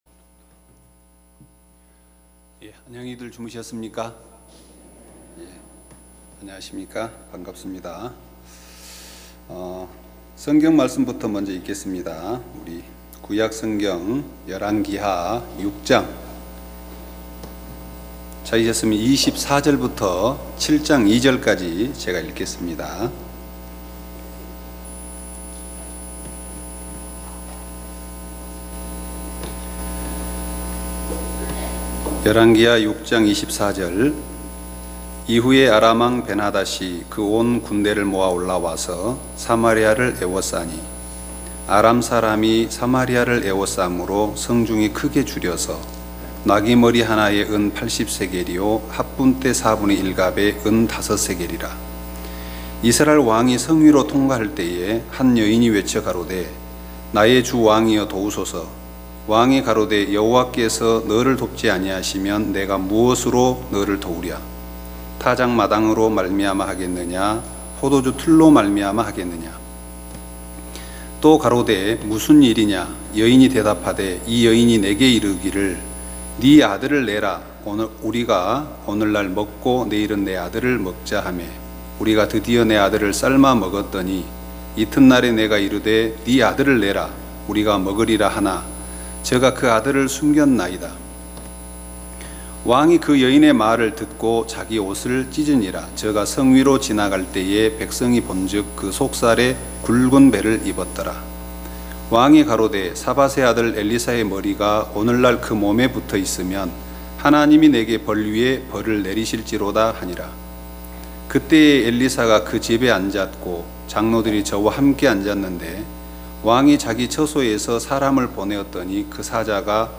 매년 굿뉴스티비를 통해 생중계 됐던 기쁜소식 선교회 캠프의 설교 말씀을 들어보세요.